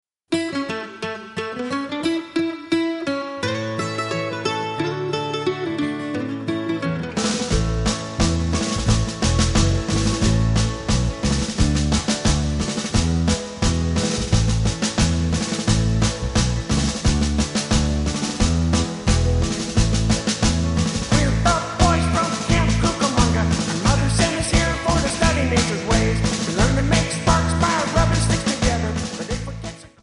MPEG 1 Layer 3 (Stereo)
Backing track Karaoke
Oldies, Country, 1950s